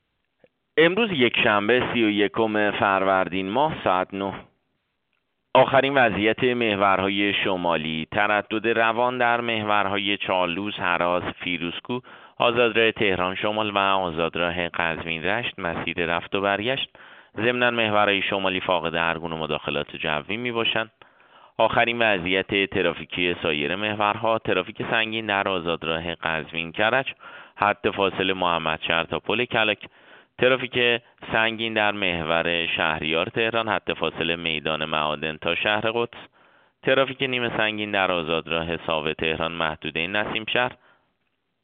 گزارش رادیو اینترنتی از آخرین وضعیت ترافیکی جاده‌ها ساعت ۹ سی و یکم فروردین؛